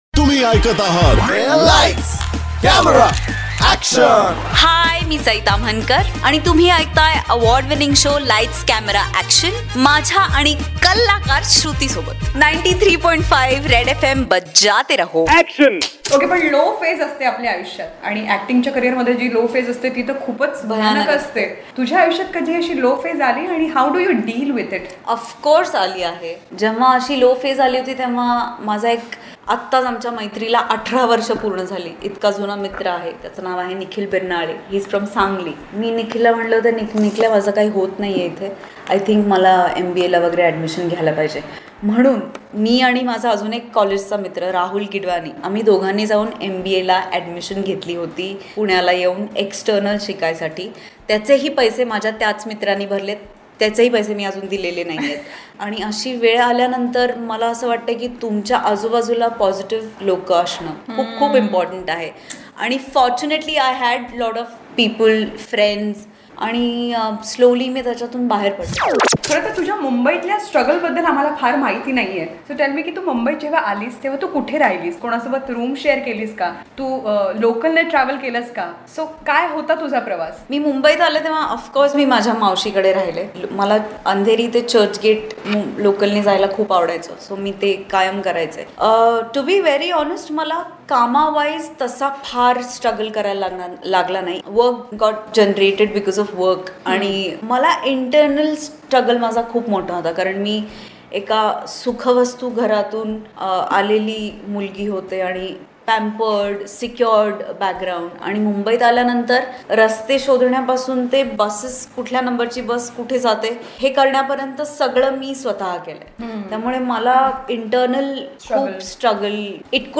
CONVERSATION WITH SAI TAMHANKAR